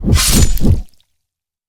Axe_00.ogg